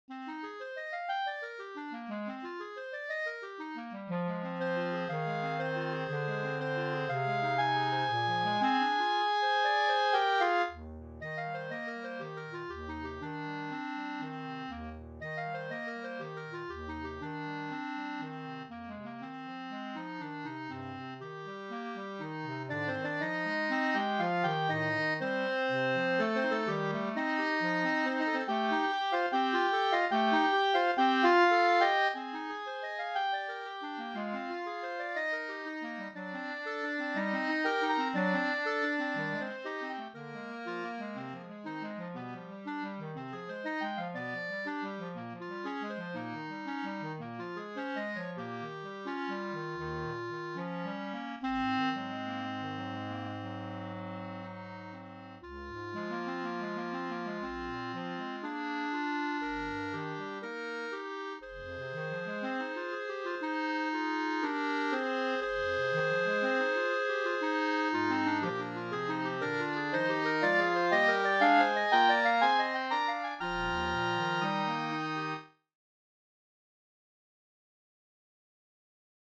This works so well for a clarinet ensemble.